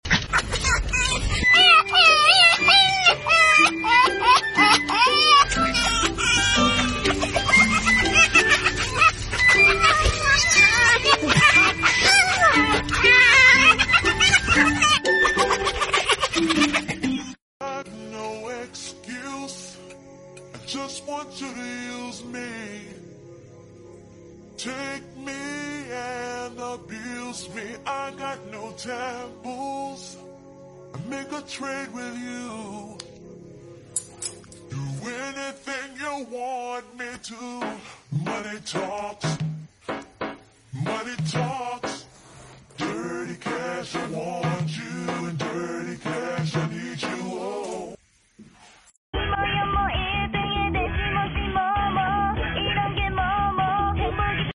Red fox is sounds like sound effects free download
Red fox is sounds like a cat meowing